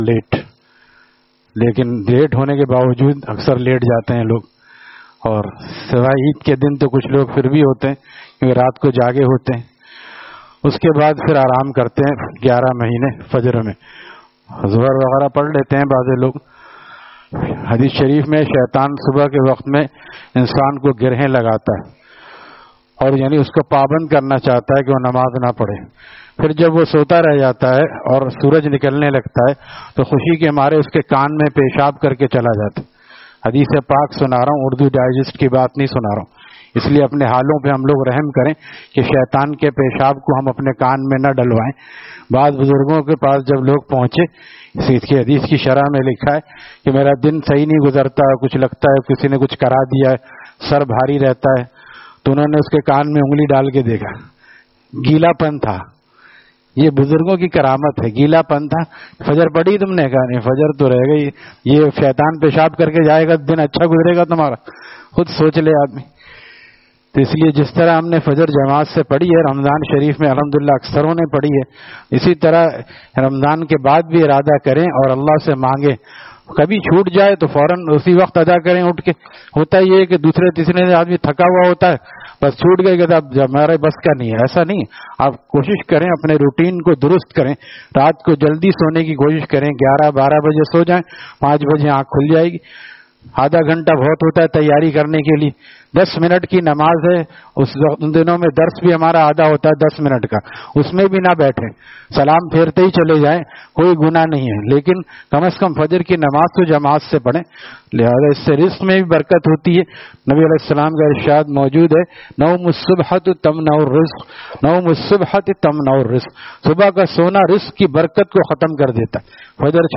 Taleem After Fajor at Jamia Masjid Gulzar e Muhammadi, Khanqah Gulzar e Akhter, Sec 4D, Surjani Town